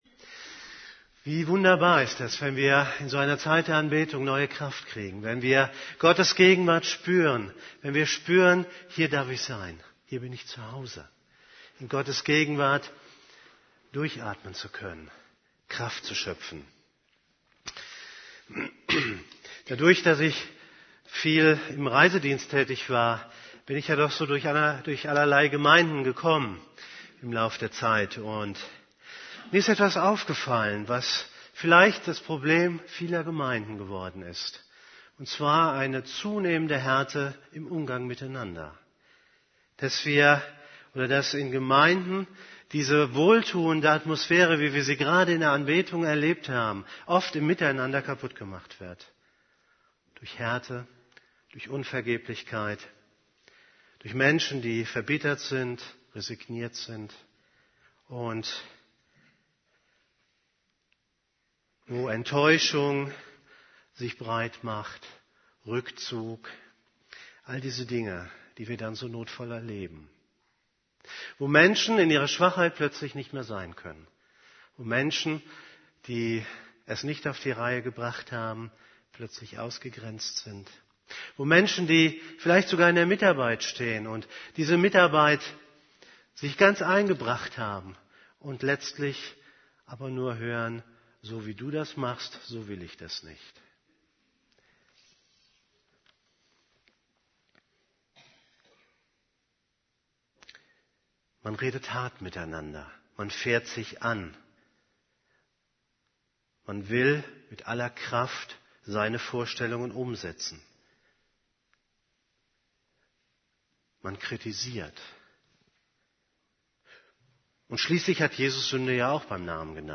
> Übersicht Predigten Zur Freiheit berufen - Einander barmherzig begegnen Predigt vom 18.